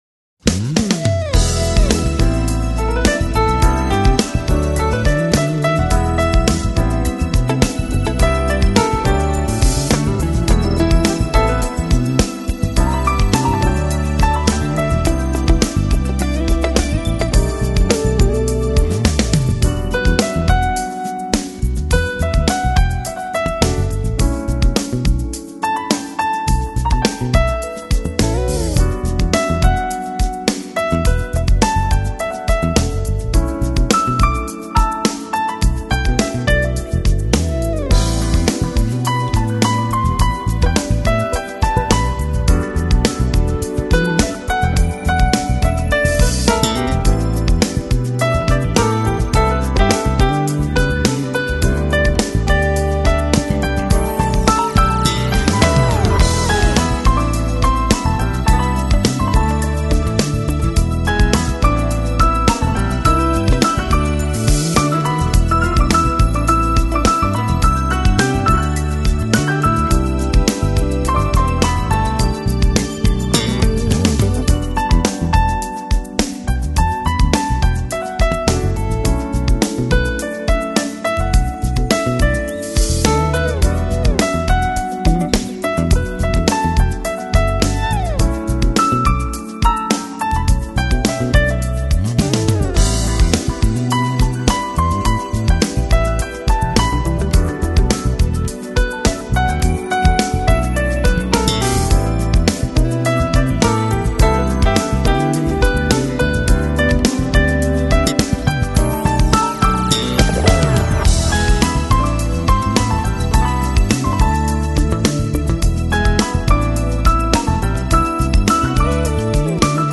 Жанр: Smooth Jazz